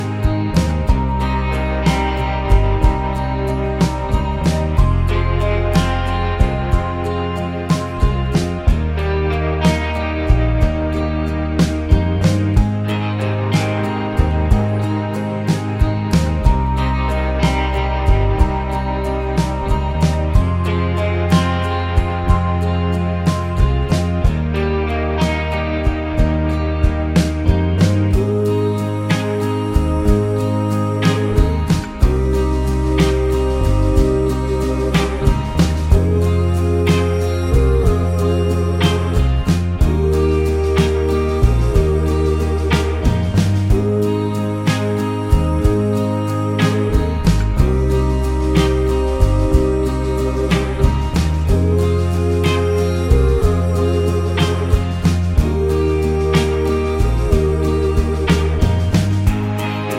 for duet Pop (2010s) 4:14 Buy £1.50